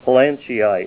Say PLANCHEITE Help on Synonym: Synonym: Bisbeeite   ICSD 100073   Katangaite   Katangite   PDF 29-576